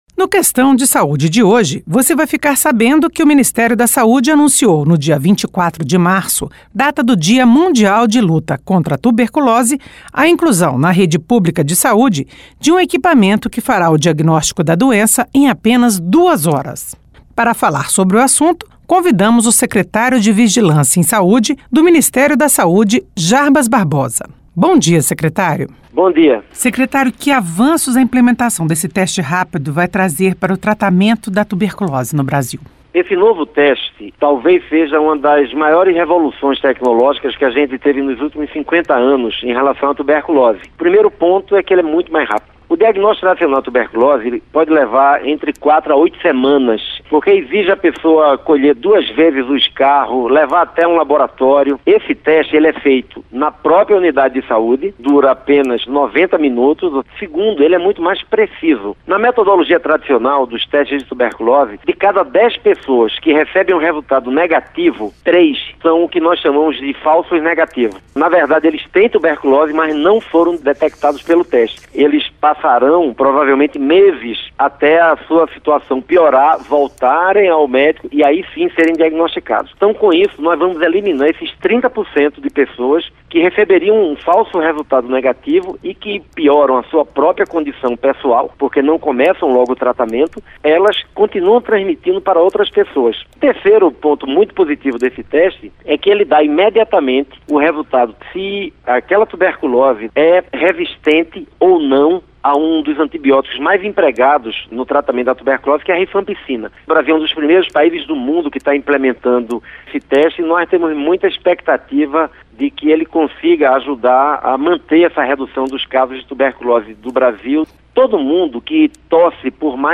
Entrevista com o secretário de Vigilância em Saúde do Ministério da Saúde, Jarbas Barbosa.